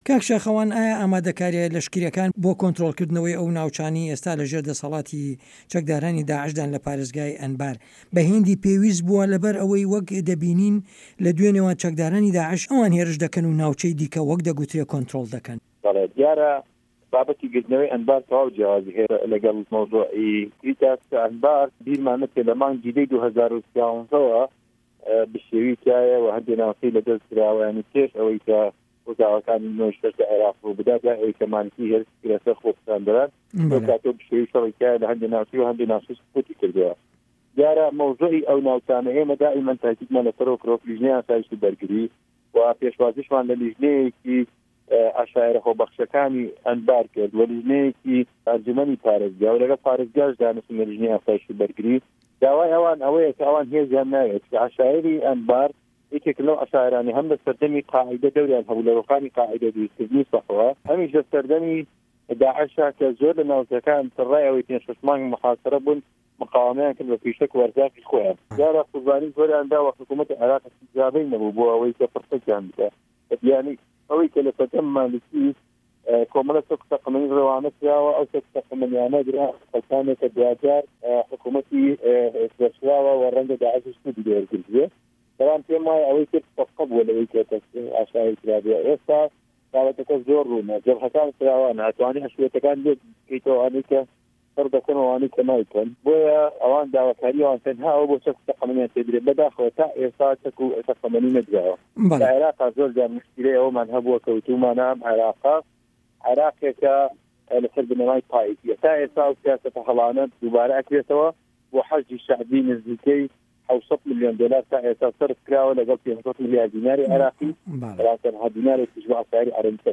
وتوێژ له‌گه‌ڵ ساخه‌وان عه‌بدوڵا